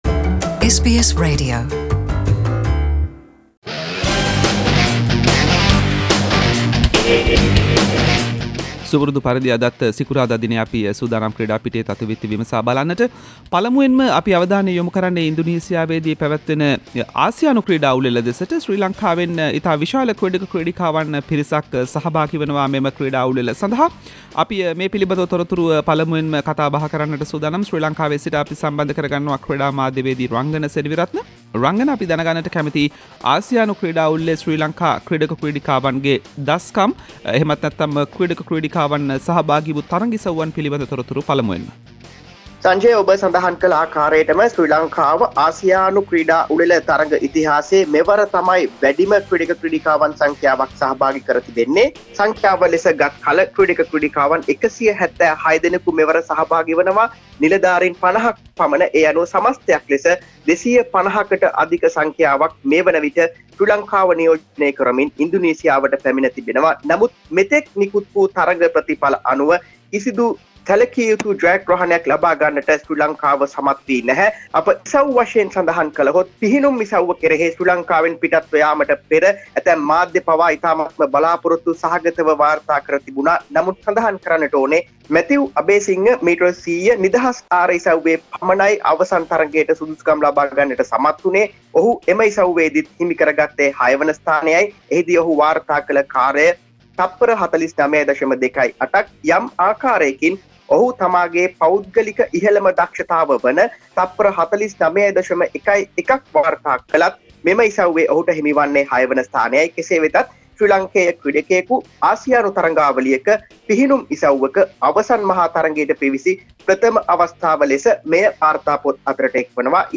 අප සමග කථා බහට